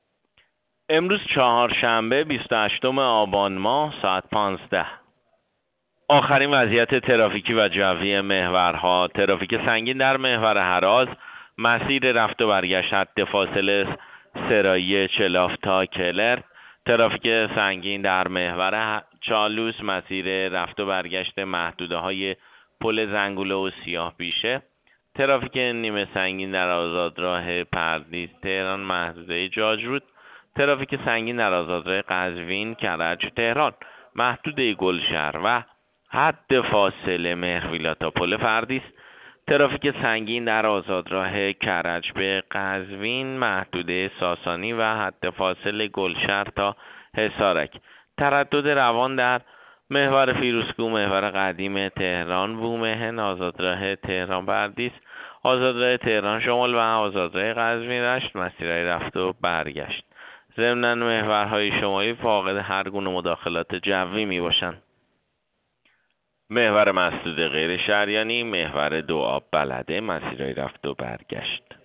گزارش رادیو اینترنتی از آخرین وضعیت ترافیکی جاده‌ها ساعت ۱۵ بیست و هشتم آبان؛